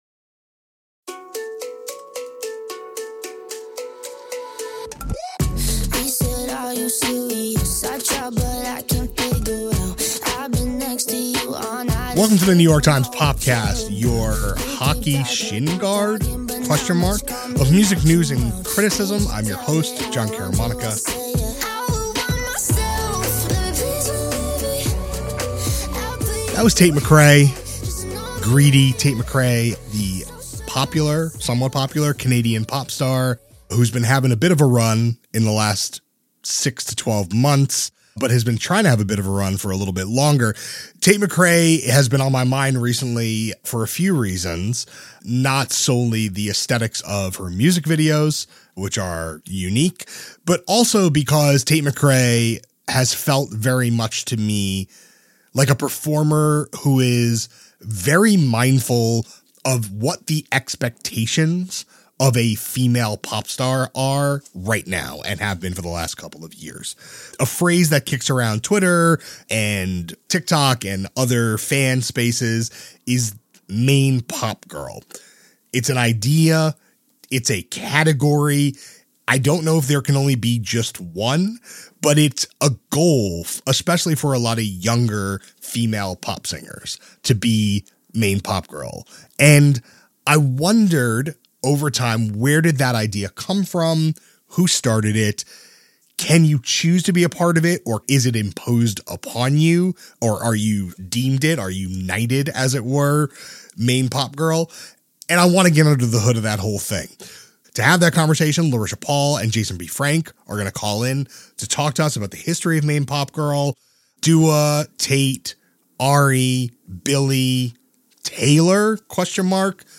A conversation about a title that emerged from stan communities, and how it’s played a role in pop singers’ careers.